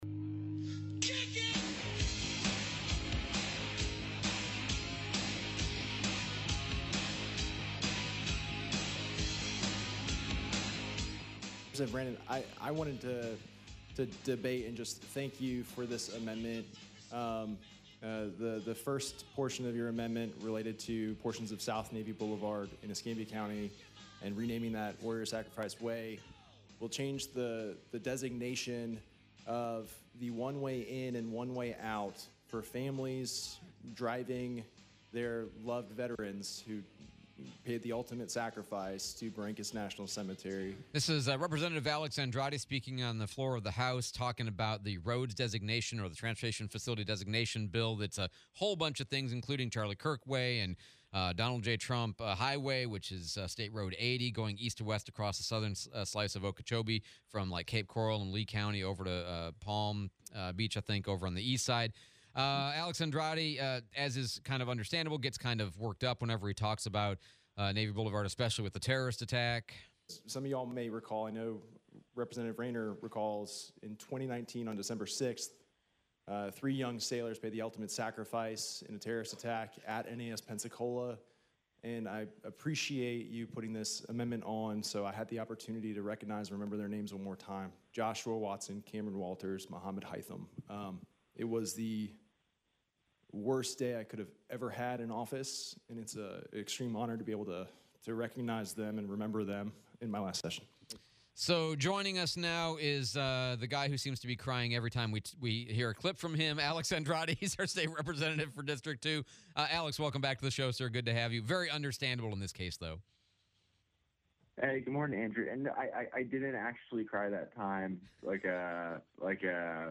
03/06/26 State Rep Andrade interview